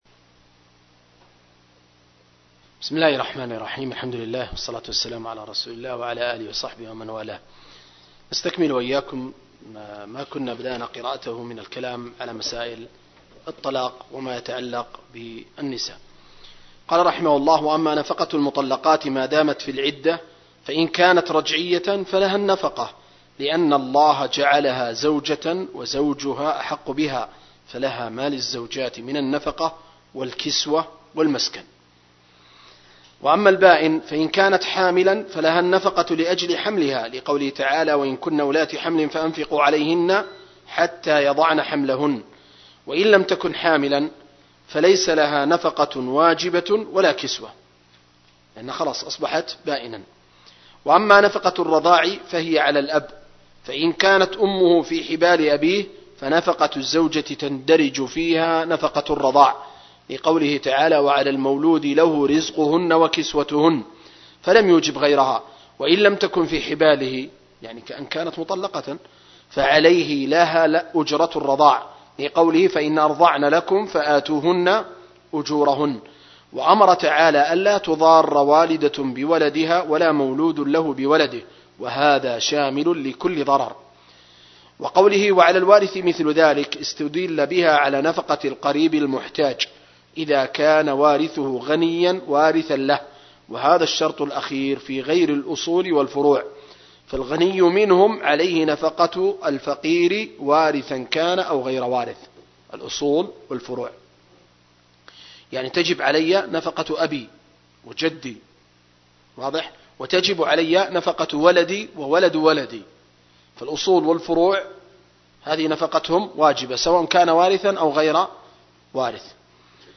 دورة علمية في قاعة الدروس والمحاضرات